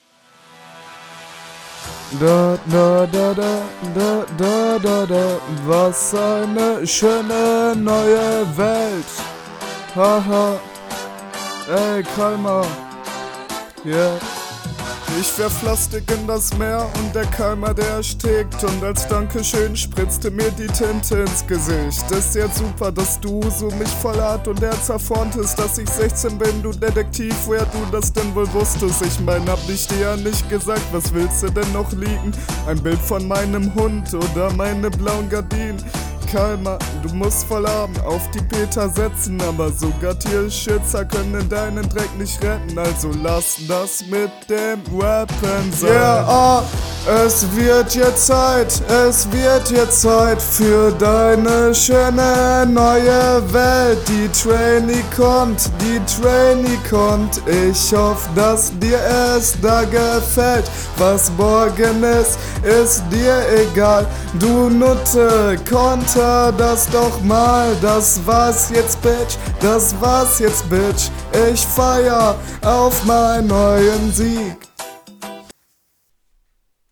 Sehr geiler Beat liebe es wenn Rapper in Battleligen mal was trauen!
Soundbild bei beiden Rappern bei Runde 2 nicht so nice wie bei Runde 1.